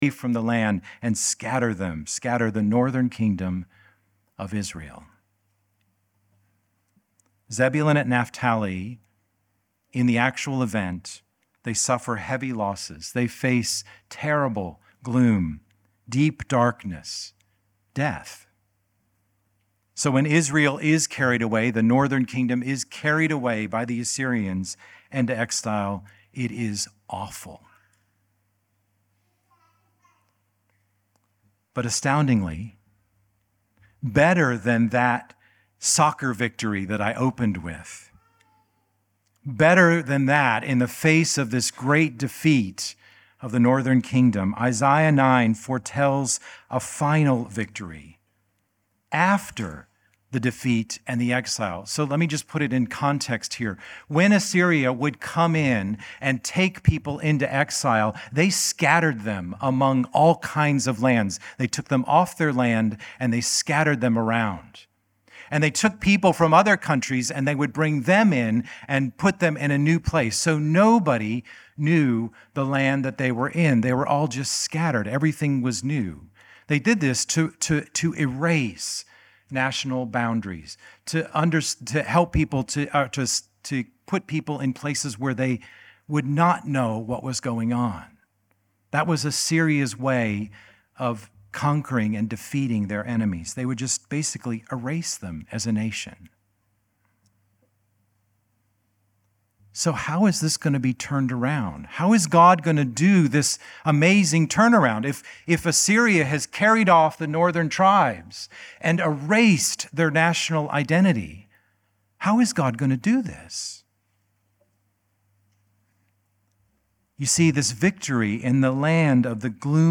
Home About Our Team Safe Churches Sunday Service Connect Events Sermons Resources Westminster Project Isaiah 9:1-7 December 16, 2025